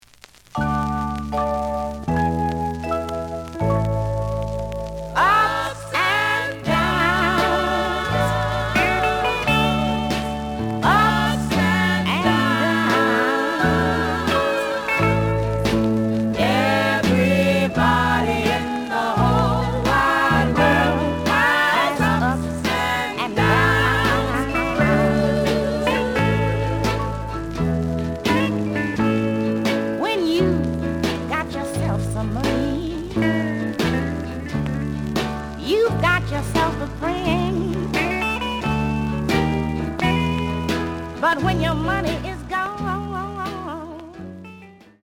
The audio sample is recorded from the actual item.
●Genre: Soul, 60's Soul
Some click noise on middle of B side due to a bubble.